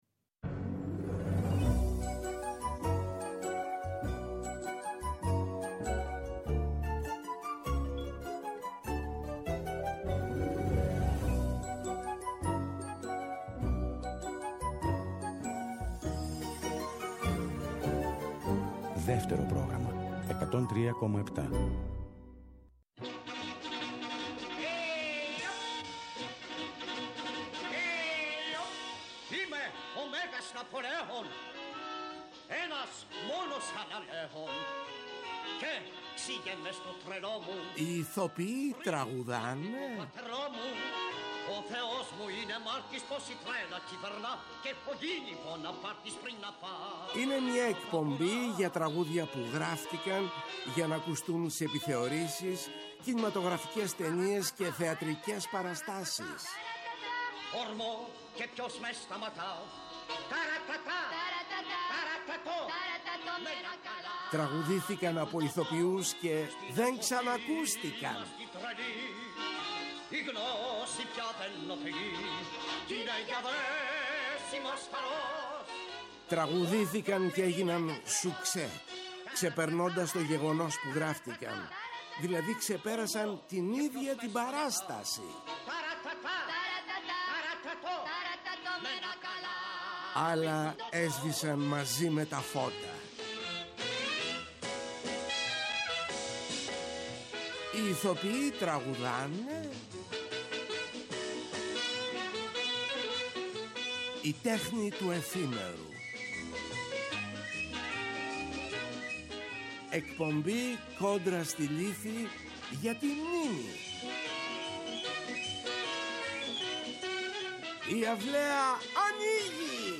Τέλος, θα απολαύσουμε τα… ρωσικά του Λευτέρη Βογιατζή και του Ηλία Λογοθέτη, λίγο πριν από την περιοδεία του έργου του Κορνάρου στη Μόσχα και την Τιφλίδα.